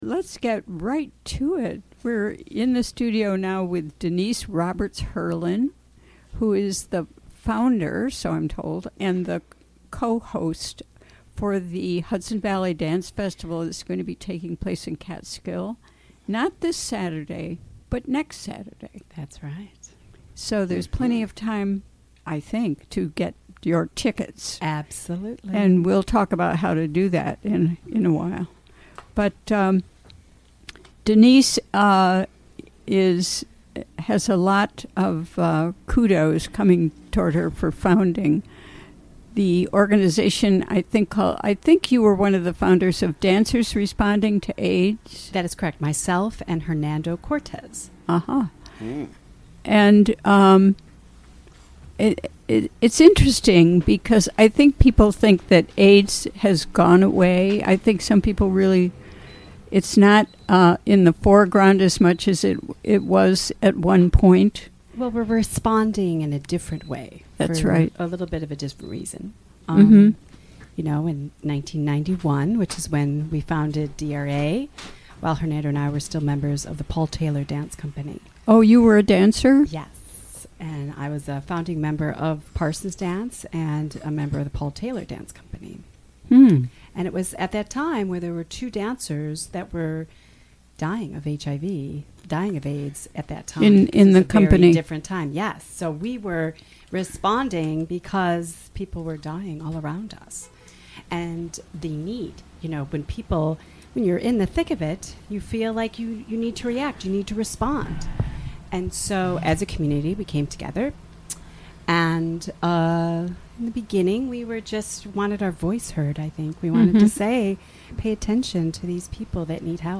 Recorded during the WGXC Afternoon Show on September 28, 2017.